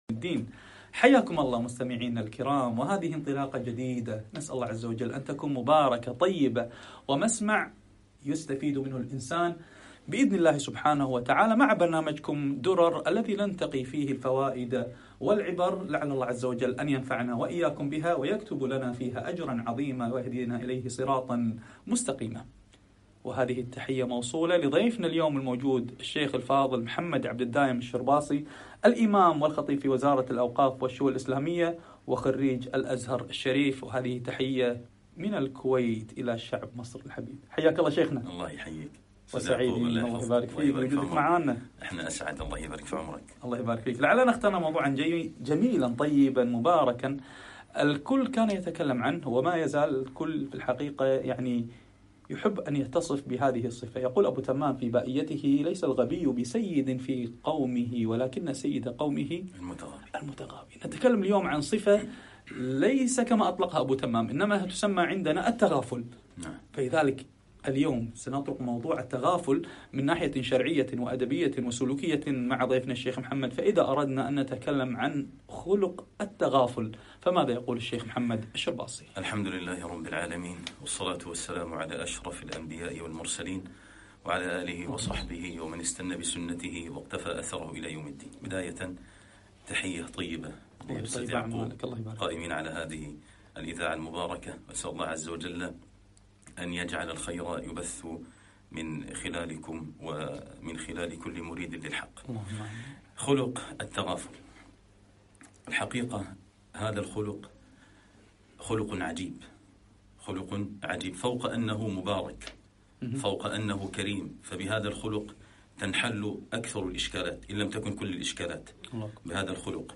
حلقة إذاعية بعنوان التغافل